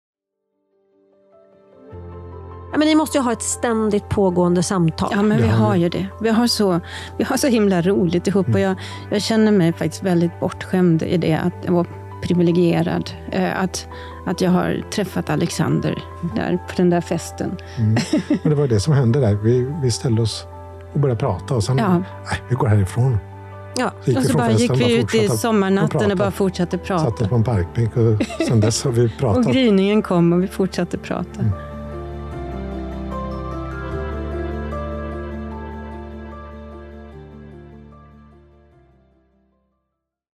Upptäck författarskapets djup genom öppenhjärtiga samtal i den nya podcasten "Jag vet vad du skrev...".